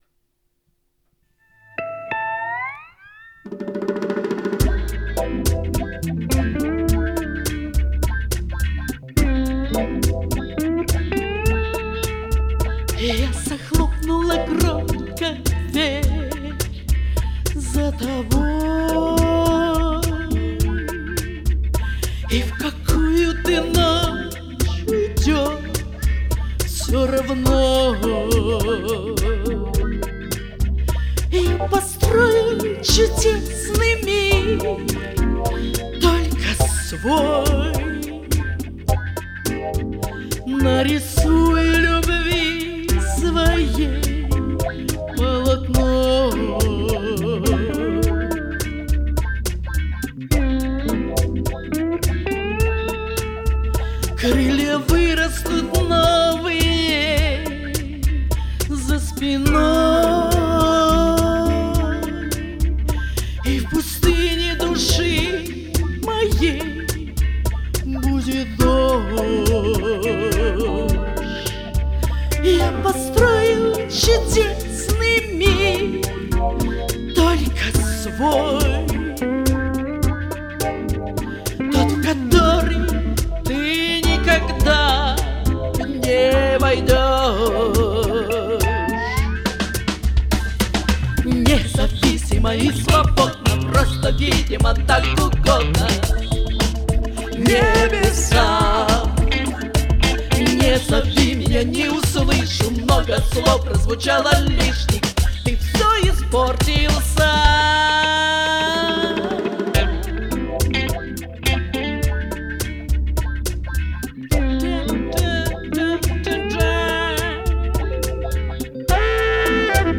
Комментарий соперника: джаз,блюз.